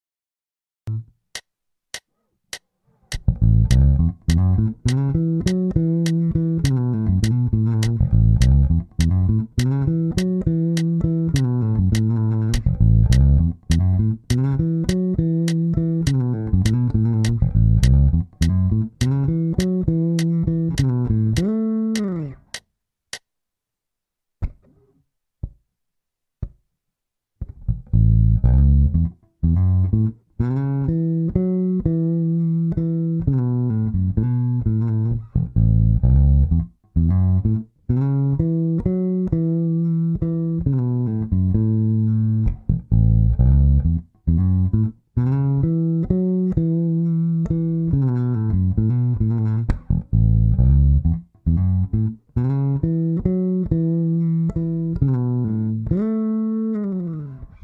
And you can hammer, slide and pulloff into the notes.